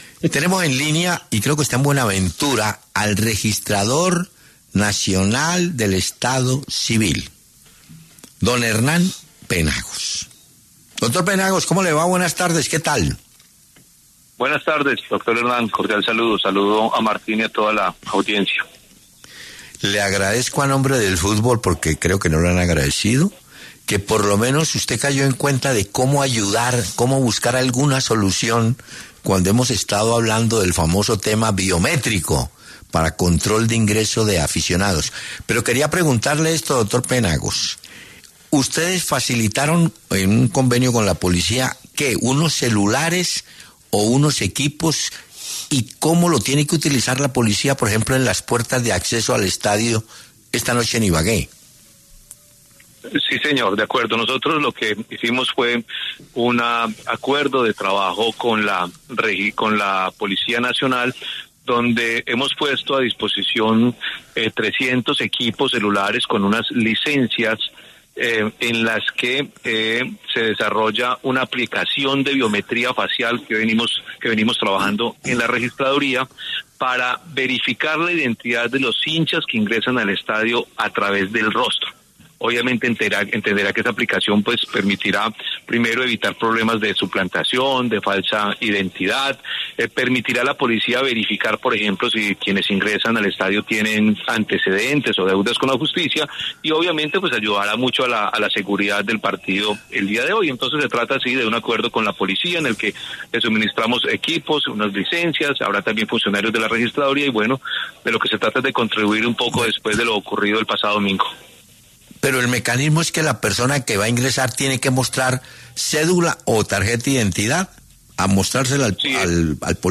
Hernán Penagos, registrador nacional del Estado Civil, pasó por los micrófonos de Peláez De Francisco en La W para hablar sobre la nueva herramienta que implementará la Policía Nacional en la final del Fútbol Profesional Colombiano entre el Deportes Tolima y Atlético Nacional, primer juego que se jugará en el estadio Manuel Murillo Toro de Ibagué.